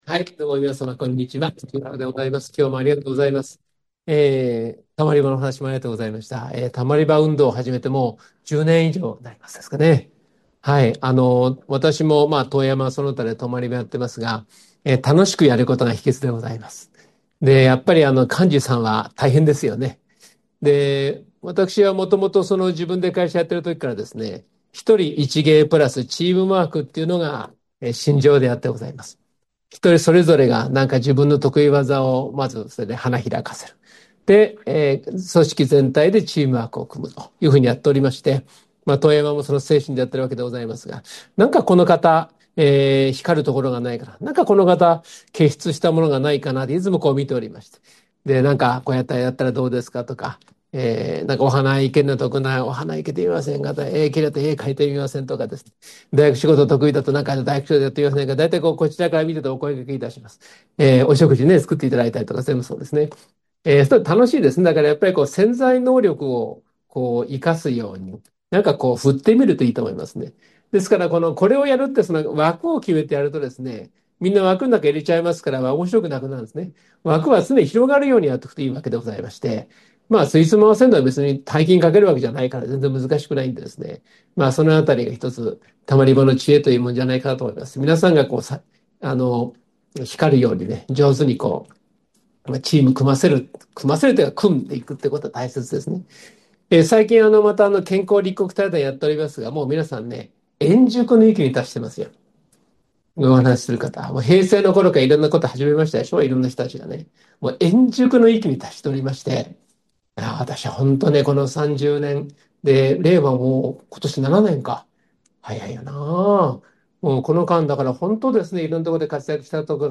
第105回NSP時局ならびに日本再生戦略講演会